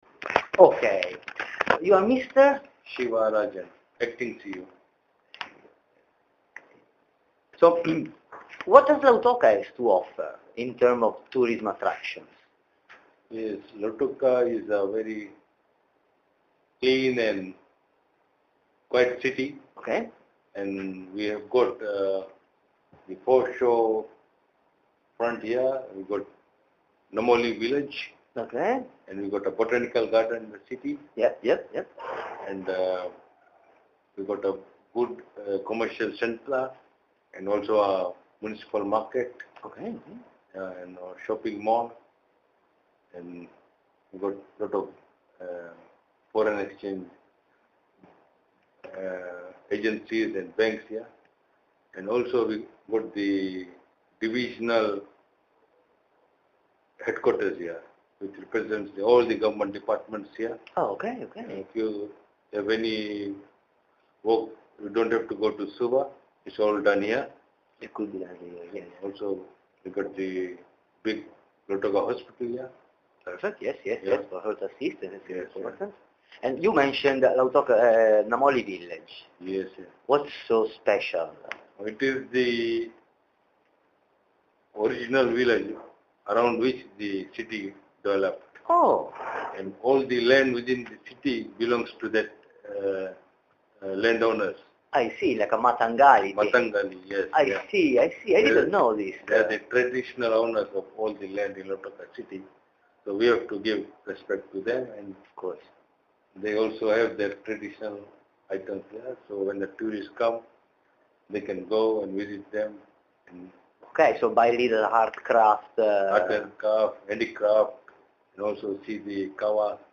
Lautoka citta' turistica? Intervista esclusiva con il CEO del comune della citta' dello zucchero